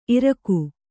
♪ iraku